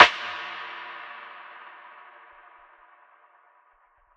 VERB SNARE.wav